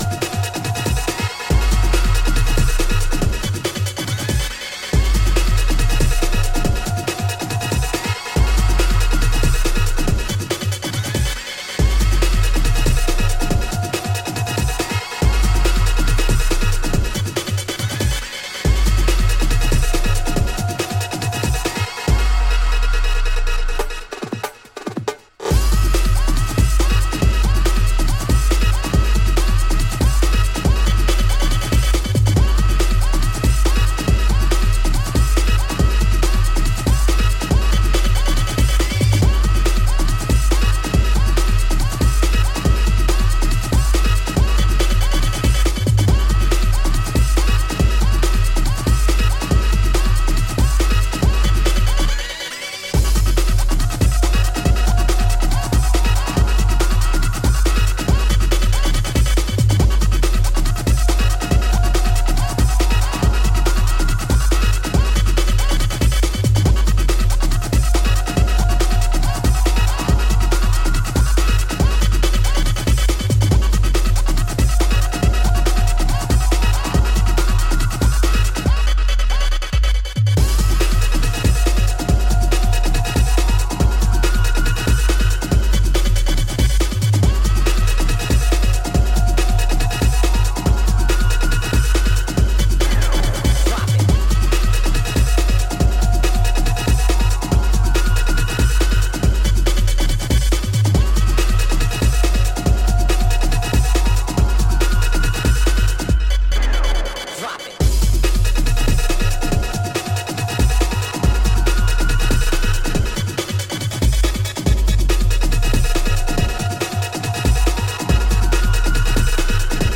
疾走するハードコア/初期ジャングル
ディープでファットな低音が気持ち良い、バッチリ世界水準のフロアチューンが揃う傑作。